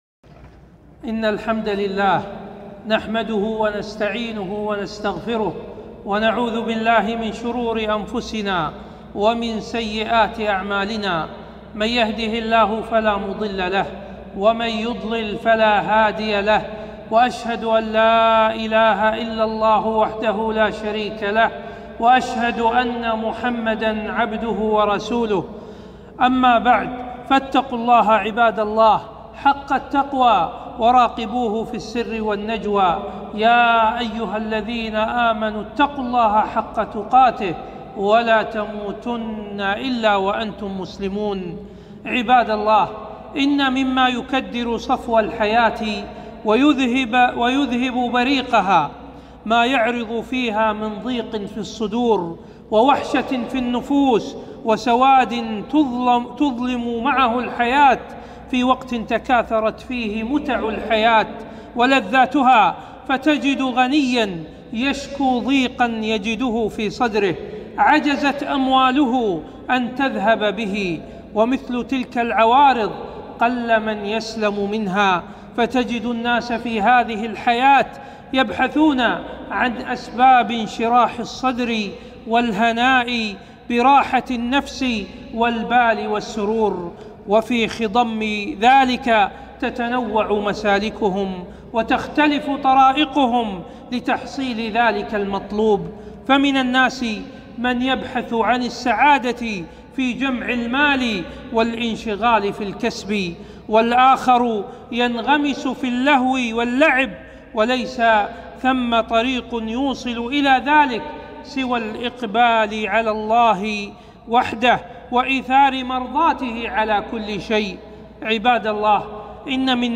خطبة - أسباب انشراح الصدر